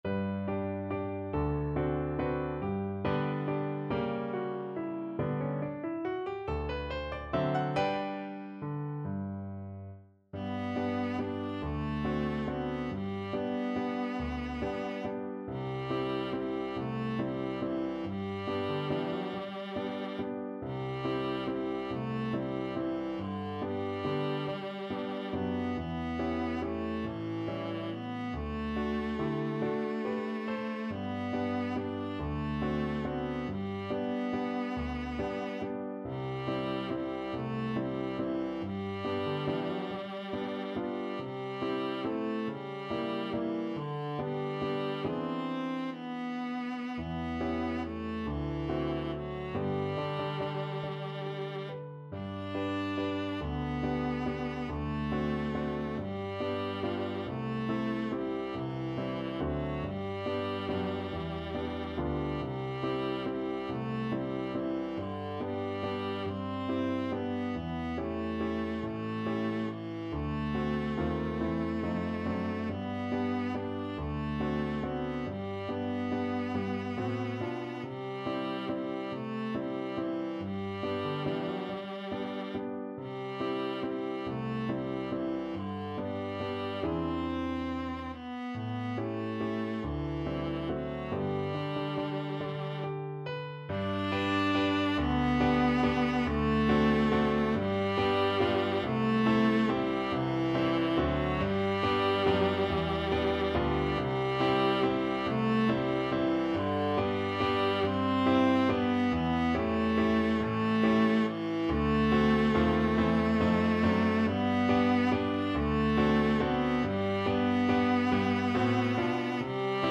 Pop Charles B. Lawlor The Sidewalks of New York Viola version
Viola
3/4 (View more 3/4 Music)
~ = 140 Tempo di Valse
G major (Sounding Pitch) (View more G major Music for Viola )
D4-D5
Pop (View more Pop Viola Music)
side_walks_new_york_VLA.mp3